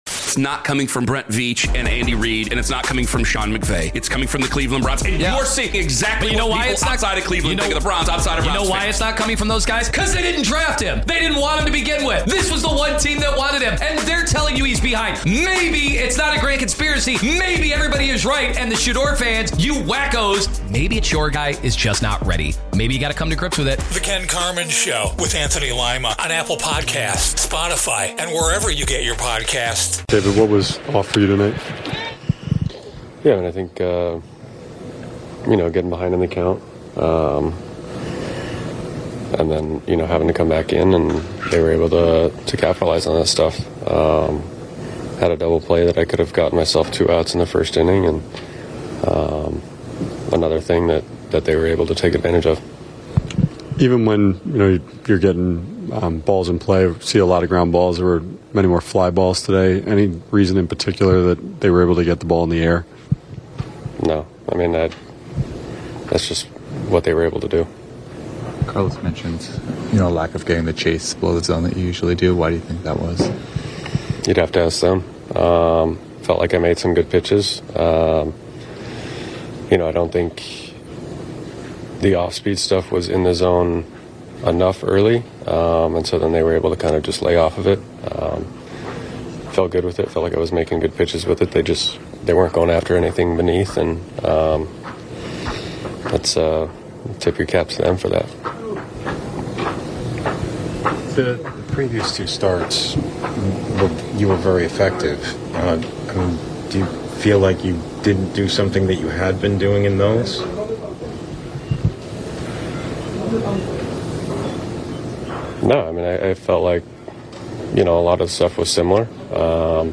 It was a difficult start for David Peterson as he was only able to go 2+ innings where he gave up 8 earned runs and after the being credited with a no decision he met with the media to break down his outing and what he feels went wrong.